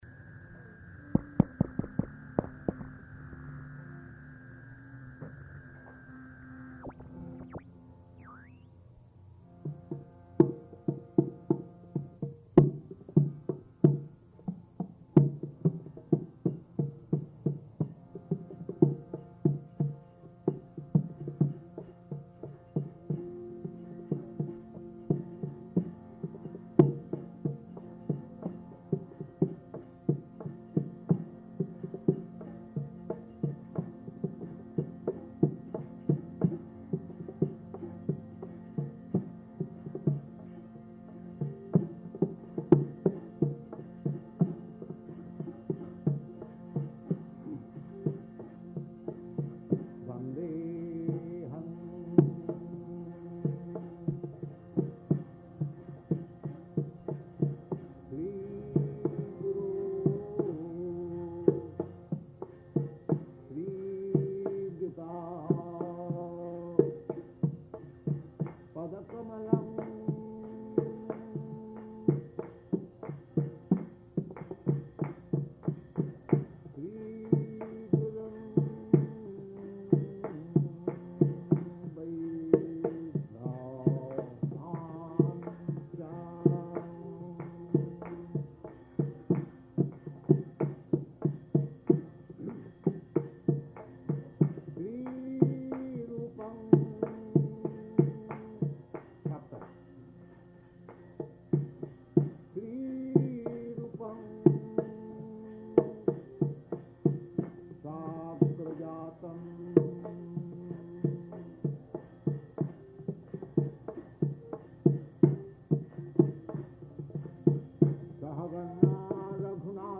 Bhagavad-gītā 5.3–7 --:-- --:-- Type: Bhagavad-gita Dated: August 24th 1966 Location: New York Audio file: 660824BG-NEW_YORK.mp3 Prabhupāda: [ kīrtana ] [ prema-dhvani ] Gaura-Premanandi.